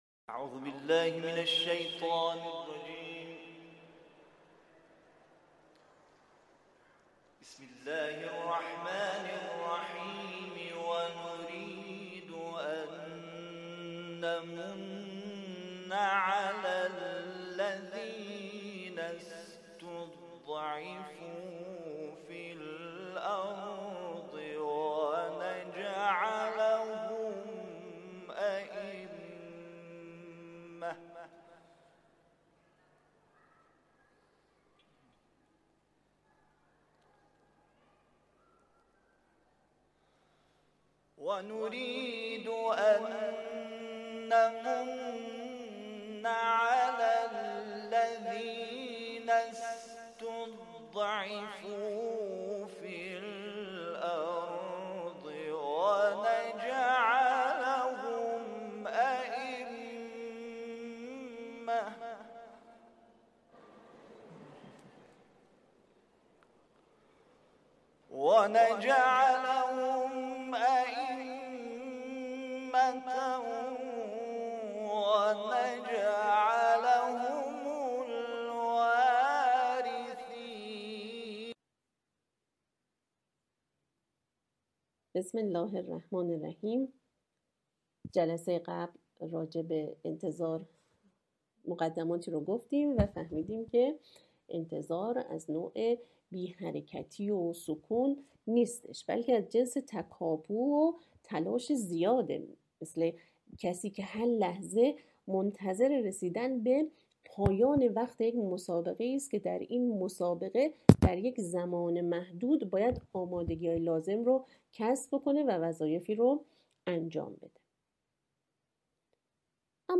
متن سخنرانی